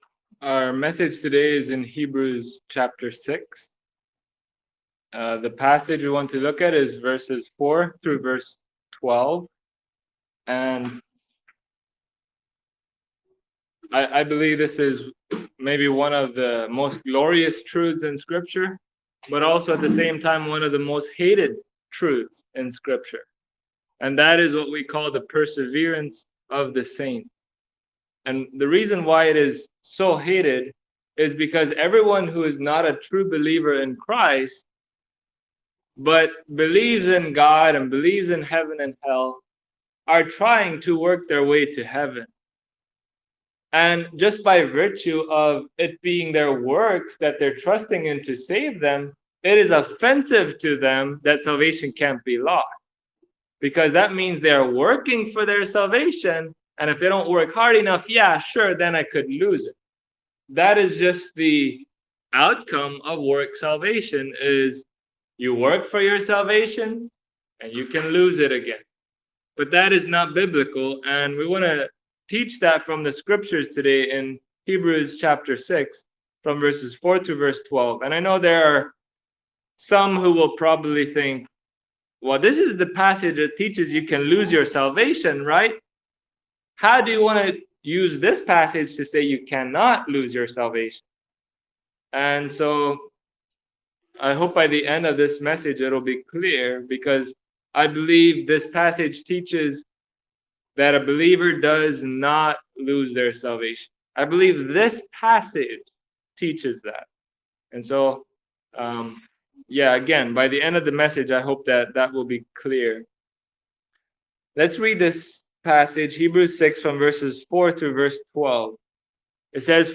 Does Hebrews 6 teach that Believers can lose their salvation? Listen to this exposition of what many consider a “controversial” text, and discover its true interpretation in light of the rest of Scripture.
Hebrews 6:4-12 Service Type: Sunday Morning Does Hebrews 6 teach that Believers can lose their salvation?